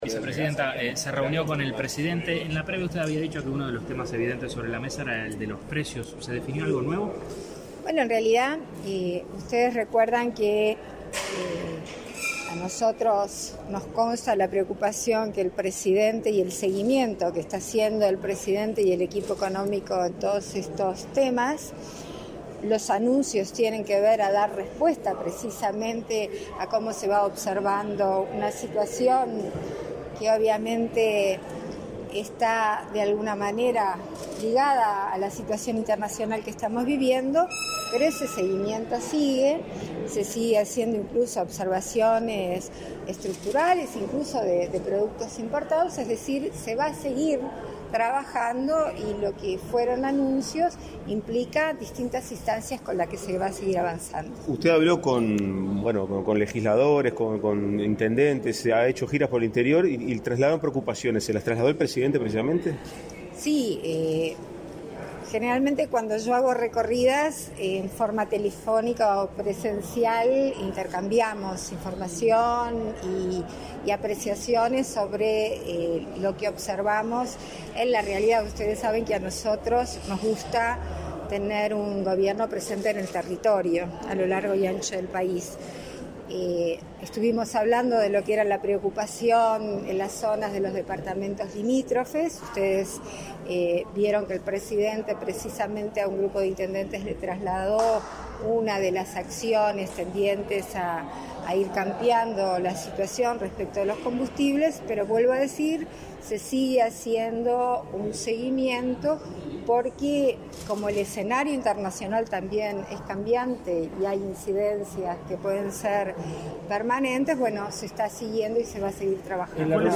Declaraciones a la prensa de la vicepresidenta de la República, Beatriz Argimón
Con la presencia del presidente de la República, Luis Lacalle Pou, este 21 de abril se realizó el lanzamiento de Sembrando Belleza por un Futuro, programa de capacitación en el área de peluquería para mujeres privadas de libertad. Tras el evento, la vicepresidenta, Beatriz Argimón, efectuó declaraciones a la prensa.